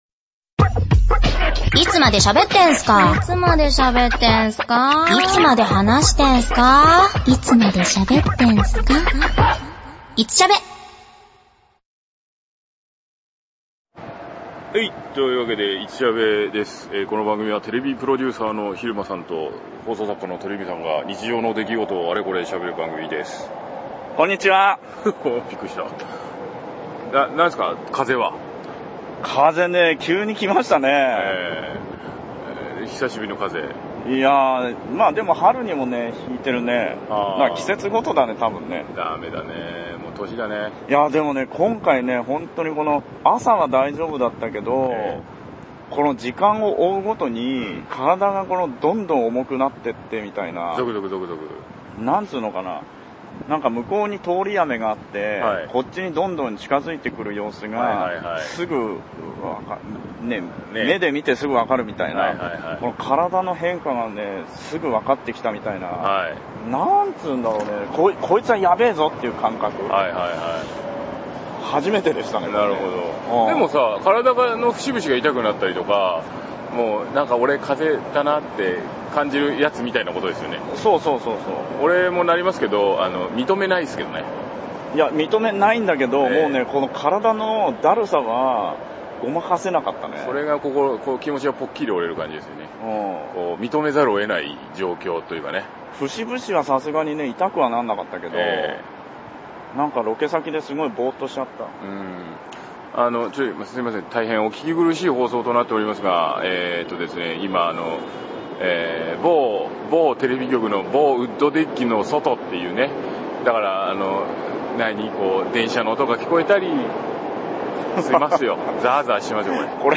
今回は、某局のウッドデッキからお届けします。 まぁ、路上です。 お聞き苦しい箇所もありますが、お許し下さい。 気になった女の子を語り合う「今週のピンナップガール」 特集のコーナーでは、文房具をテーマに喋っております。